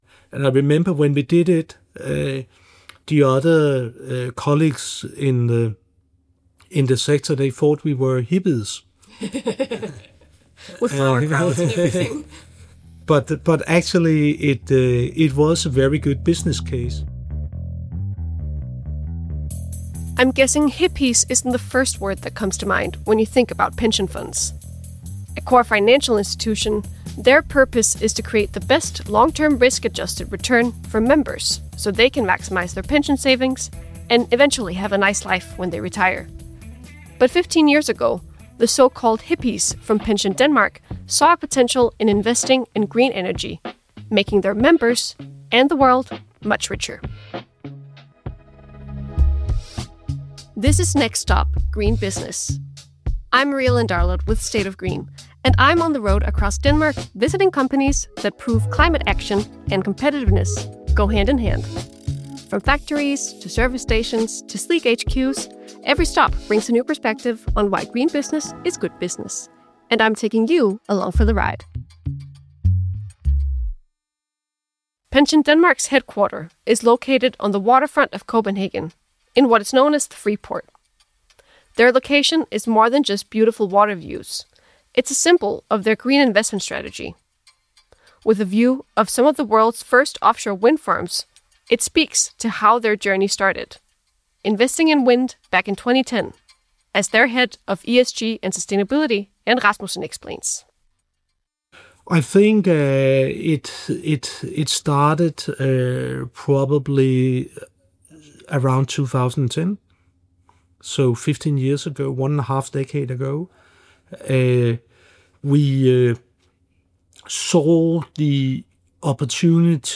In this episode we visit PensionDanmark at their headquarters in Copenhagen. Being a first mover is not always easy, but 15 years ago, Pension Denmark saw a potential in investing in green energy making their members – and the world - much richer.